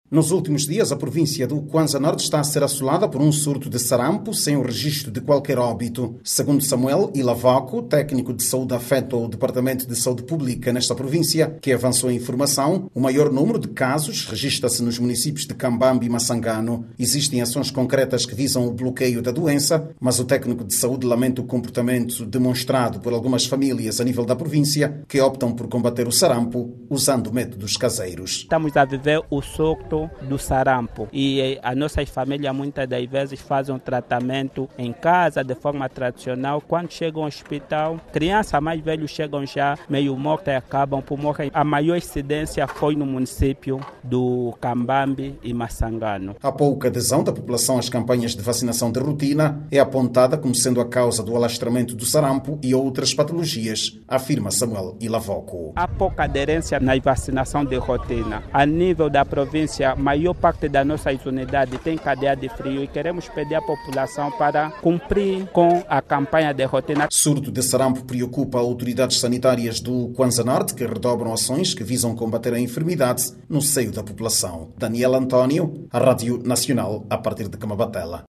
A baixa adesão da população às campanhas de vacinação é apontada como a principal causa da propagação da doença. A reportagem